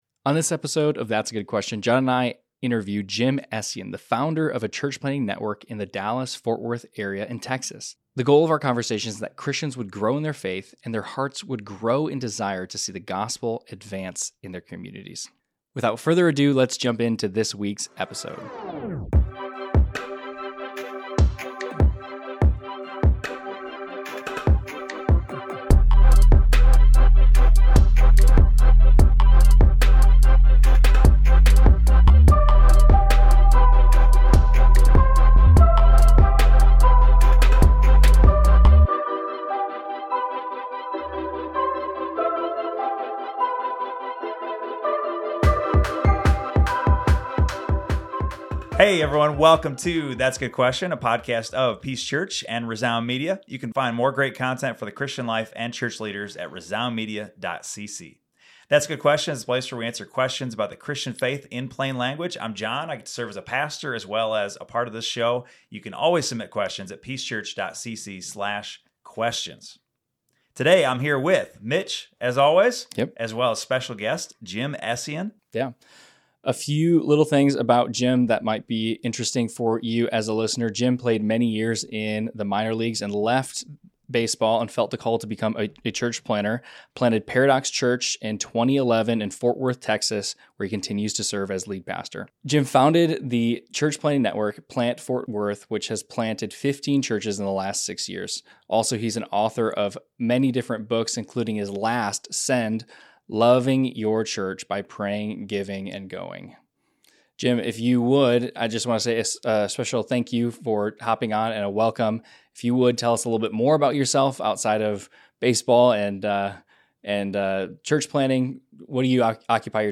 Embracing the Mission: A Conversation